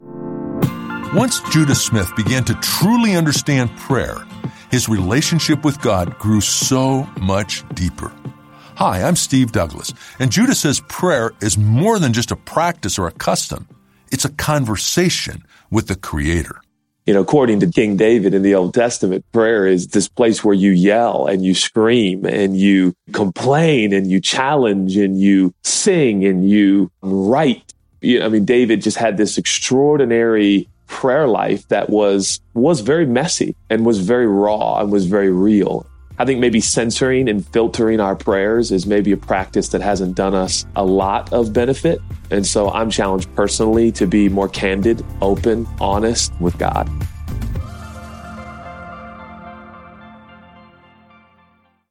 Each day, you’ll hear a short audio message with simple ideas to help you grow in your faith.